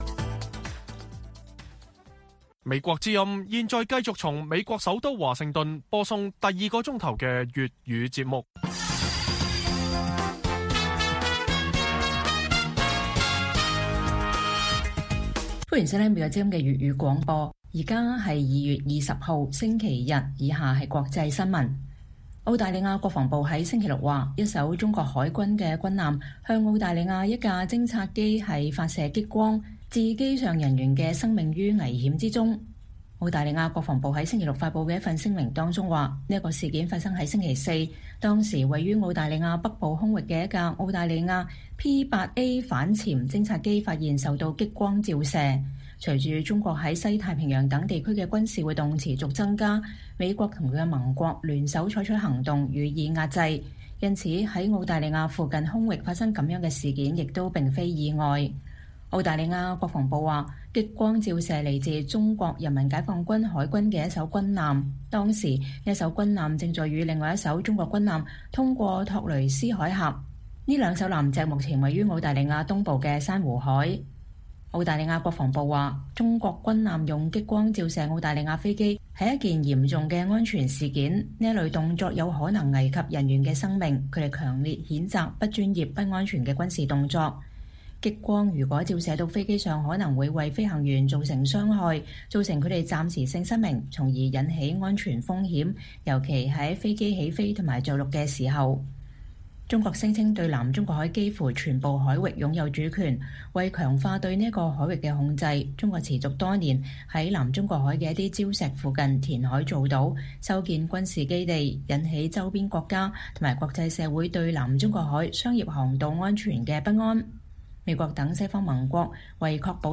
粵語新聞 晚上10-11點：澳國防部嚴厲譴責中國軍艦用激光照射澳軍飛機行為